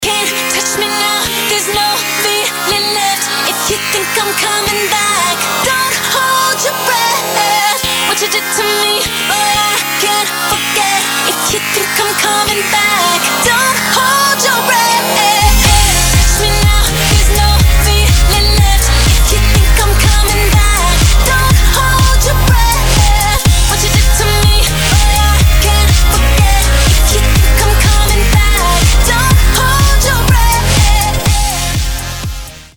• Качество: 320, Stereo
Ремикс на известную песню